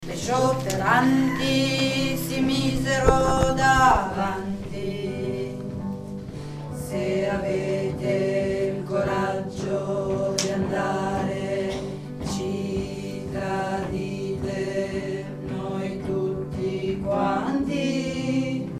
Mondine_contro_contralti_bassi.mp3